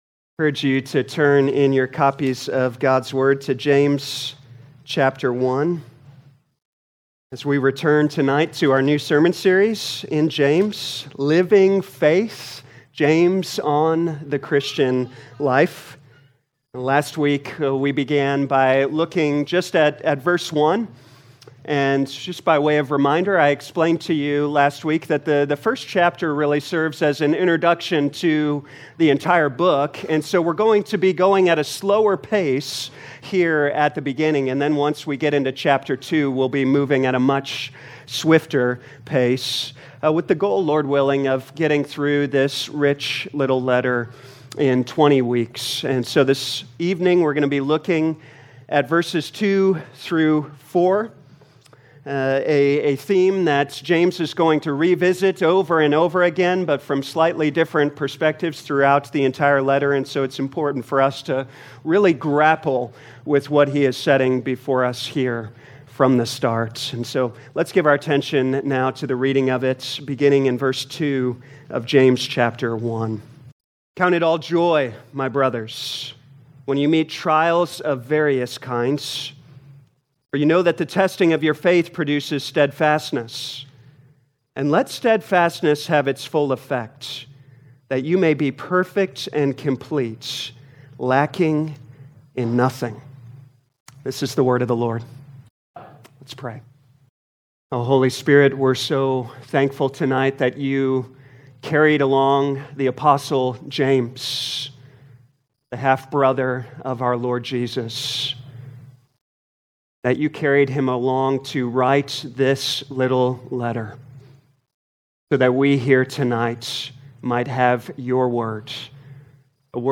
2025 James Evening Service Download